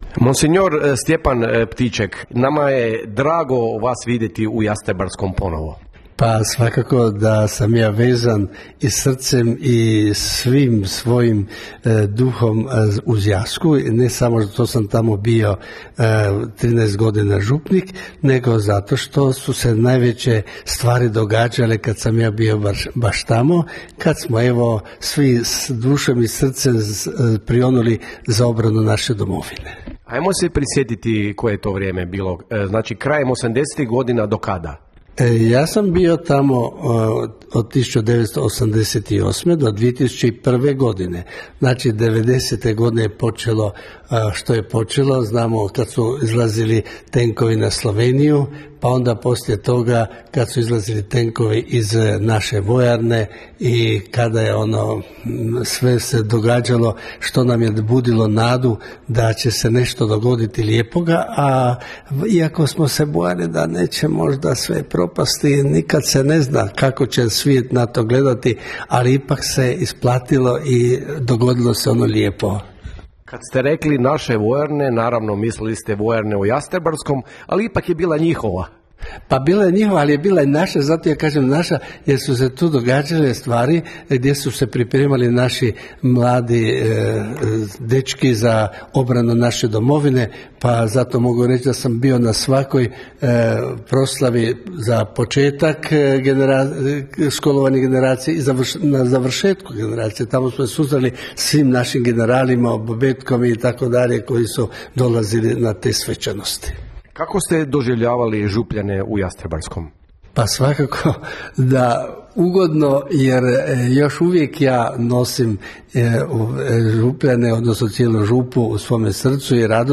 Na nedavnoj, velikoj proslavi 30. obljetnice od početka Domovinskog rata i prvog postrojavanja 61. samostalne bojne Zbora narodne garde, osim branitelja, u našem su gradu bili i brojni uvaženi gosti.